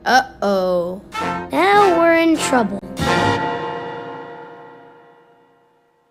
uh oh now were in trouble the backyardgians Meme Sound Effect